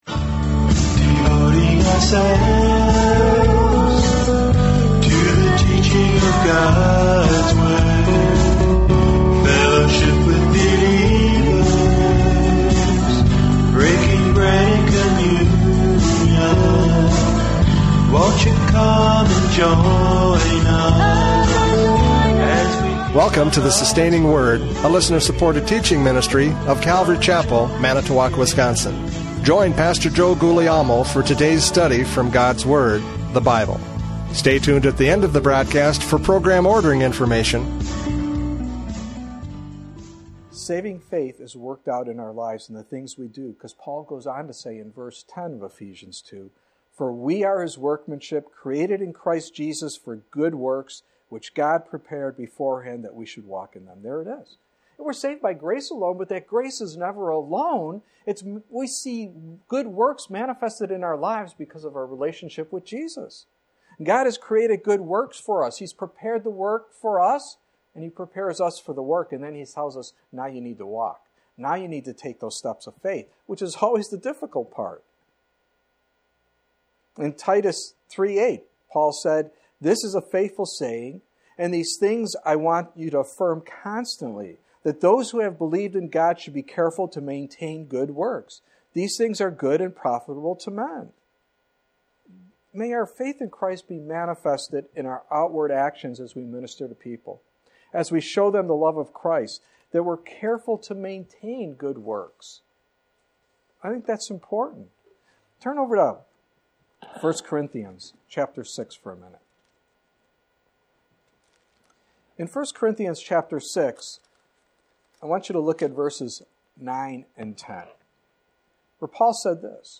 James 2:14-20 Service Type: Radio Programs « James 2:14-20 Faith is Proved by Works!